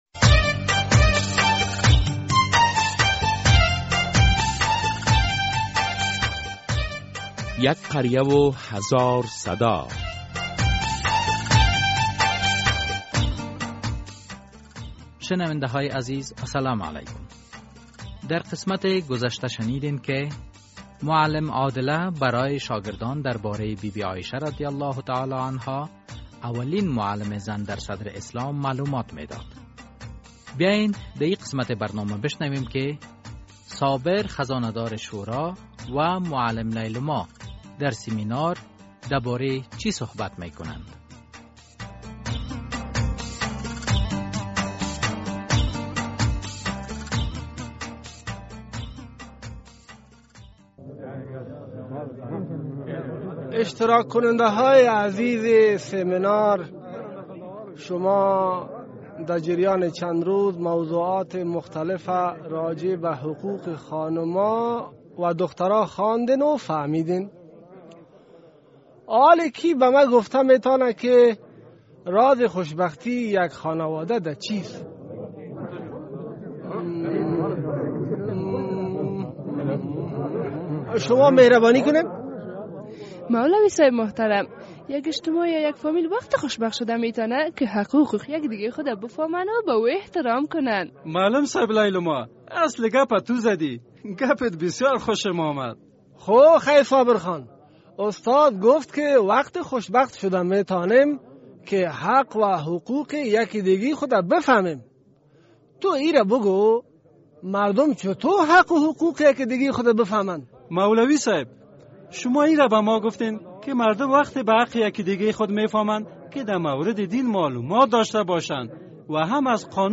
چرا نقش زنان در اجتماع با ارزش خوانده می‌شود؟ در قسمت ۱۹۲ درامه یک قریه هزار صدا این موضوع و سایر موضوعات اجتماعی بیان می‌شود ...